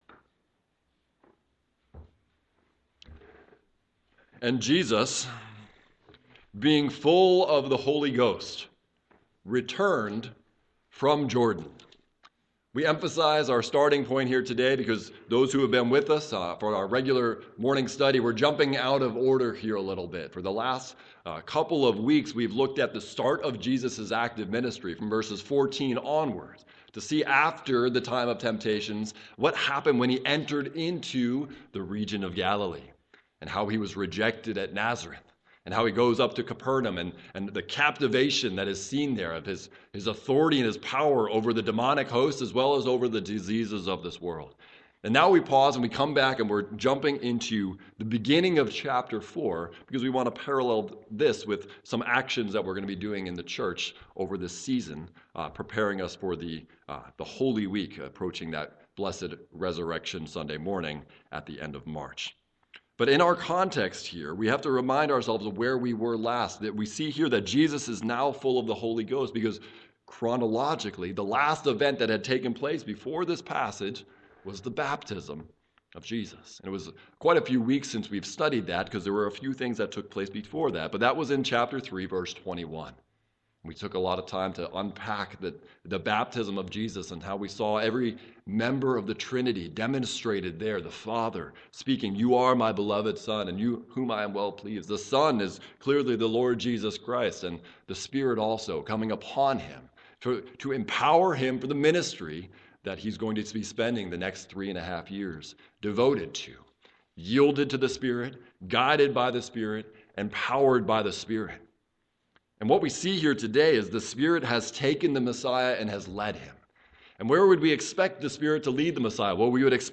Morning Worship – 02/25/24 Luke’s Gospel – Into the Wilderness [And an Intro. to Fasting] Text